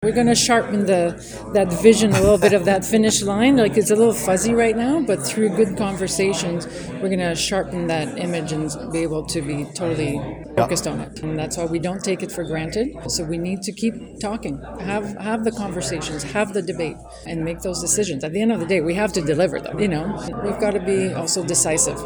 In an interview with myFM afterward, she emphasized the importance of thoughtful leadership on key priorities and promised a year of stability and clarity.